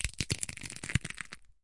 描述：在我手中碾碎芯片。 用Tascam DR40录制。
Tag: 芯片 挤压 压薄 junkfood 粉碎 松脆 芯片 嘎吱作响 糊状 紧缩 嘎吱嘎吱